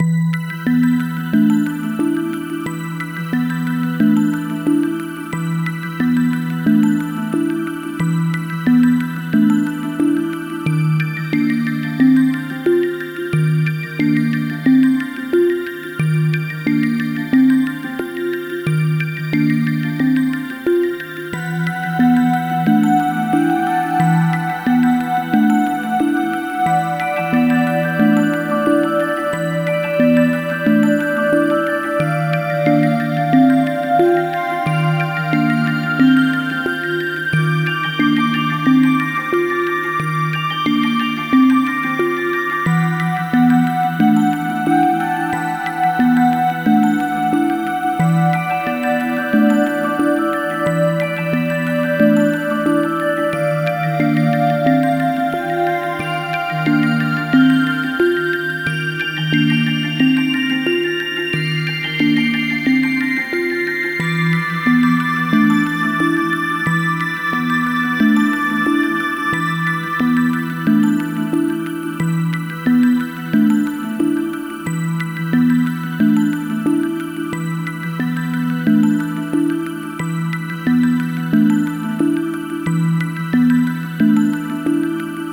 妖精の住んでいる谷をイメージしたキラキラしたシンセサイザーの曲です
かわいい系より神秘系です